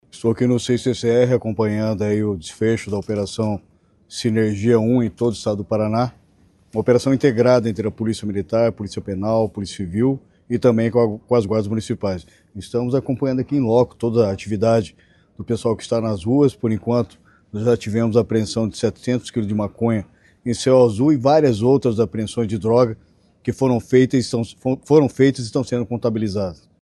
Sonora do secretário Estadual de Segurança Pública, Hudson Teixeira, sobre a Operação Sinergia